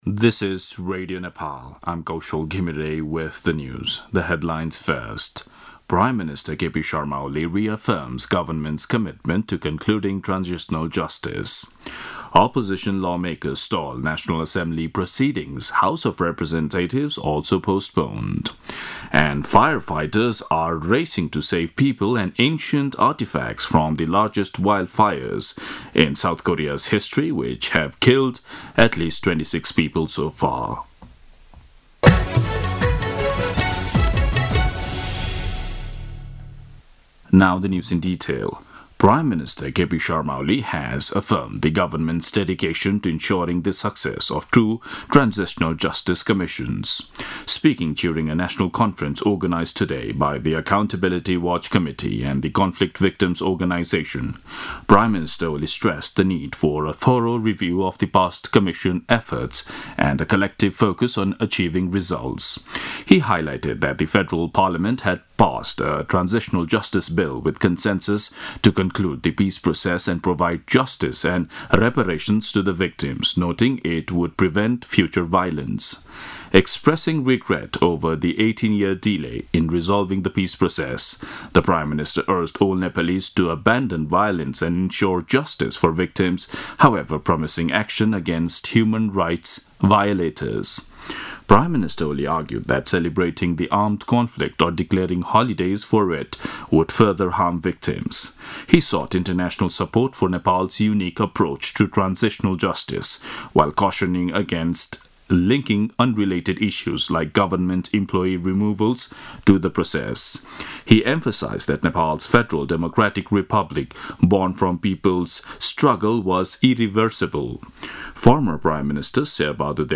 दिउँसो २ बजेको अङ्ग्रेजी समाचार : १४ चैत , २०८१